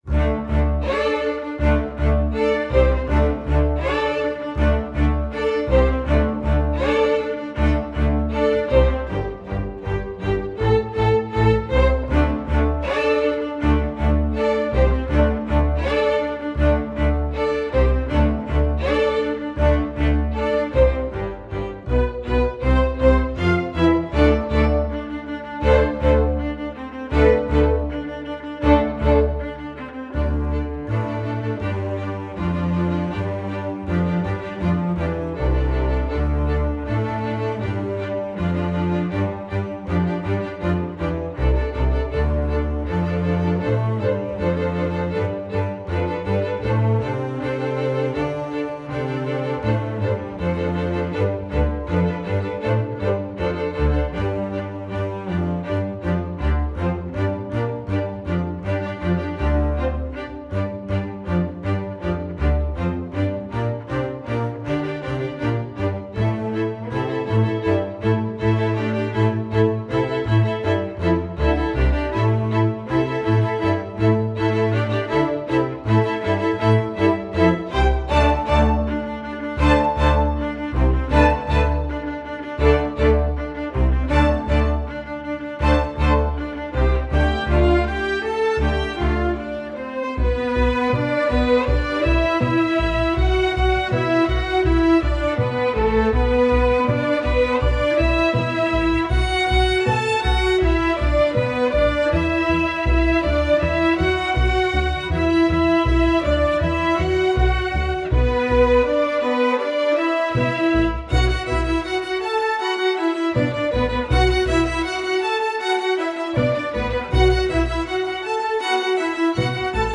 Voicing: Cell/Bass and Flex Orchestra